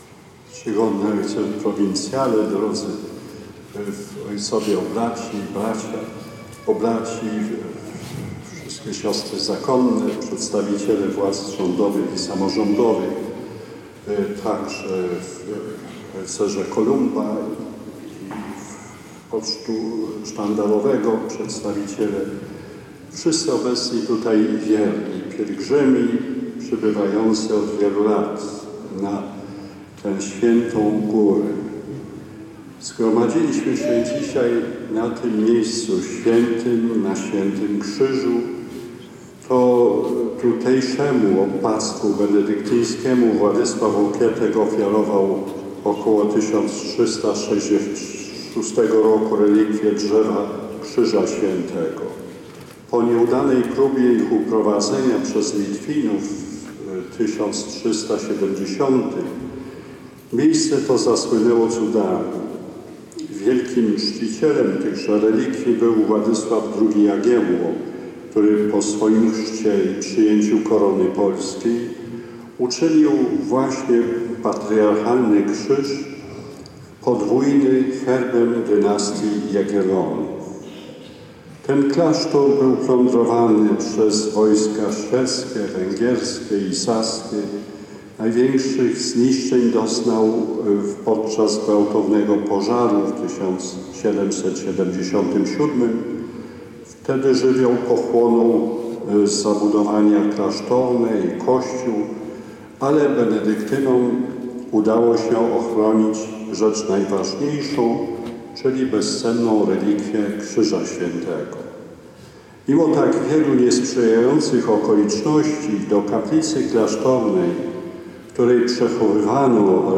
Tajemnicy krzyża poświęcił homilię podczas sumy rozpoczynającej uroczystości odpustowe na Świętym Krzyżu arcybiskup Stanisław Gądecki.
Kazanie_Gadecki-online-audio-converter.com_.mp3